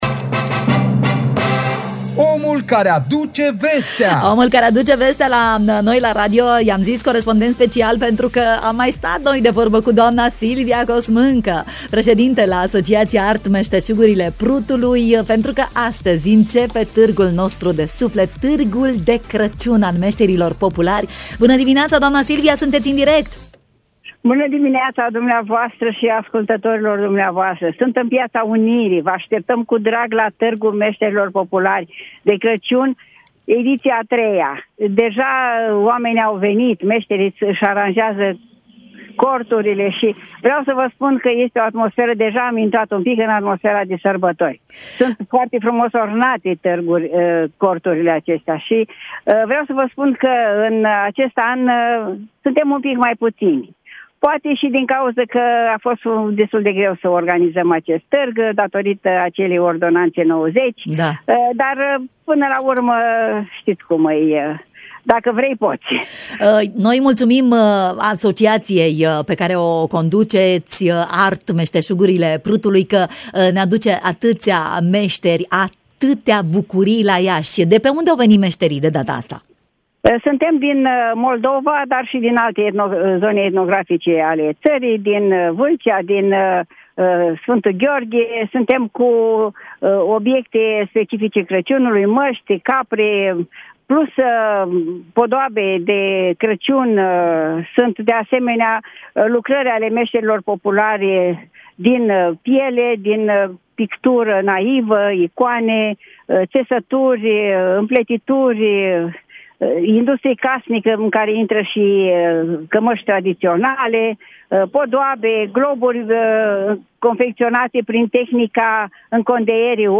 în direct în matinal Radio Iași